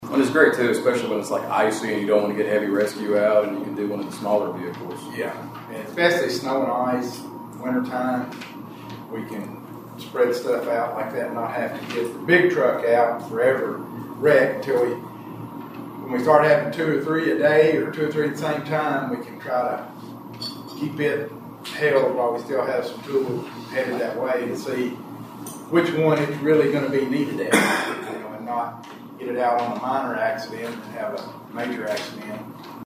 Magistrates in Caldwell County learned about significant upgrades to local emergency equipment—specifically, battery-powered rescue tools—during this week’s Fiscal Court meeting.